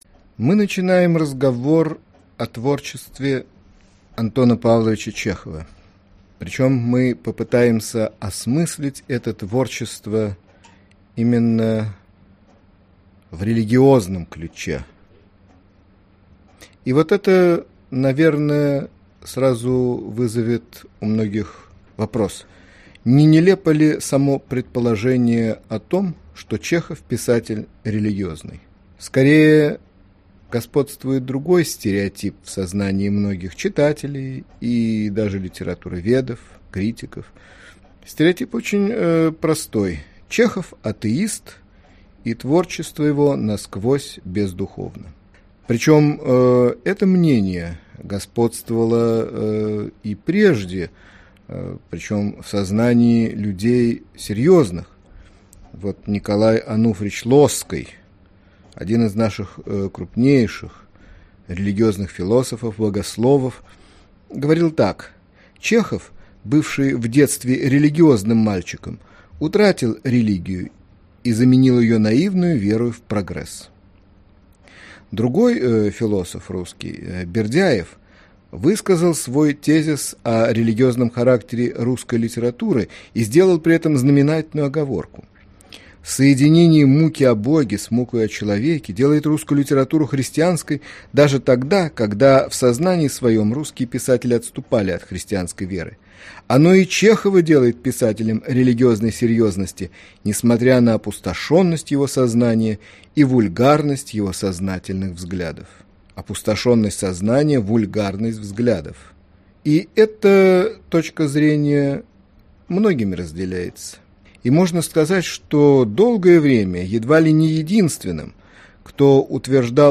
Аудиокнига Лекция